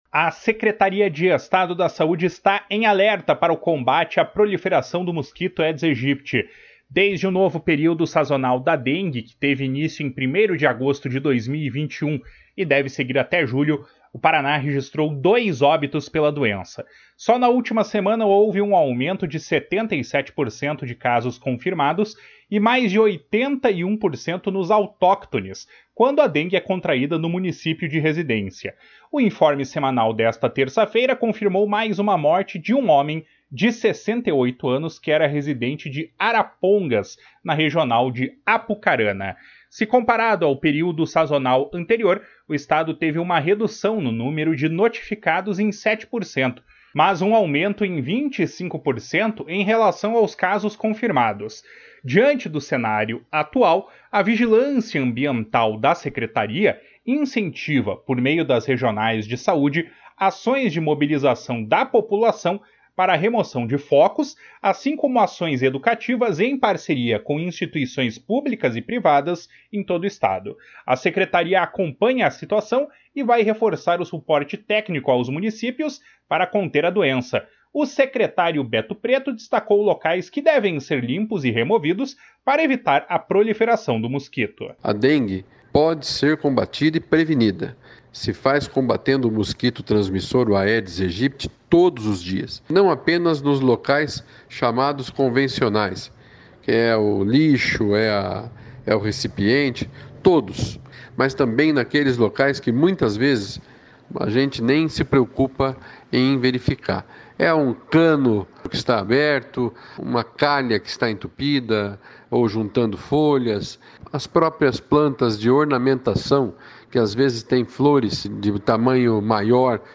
Boletim Dengue 29-03.mp3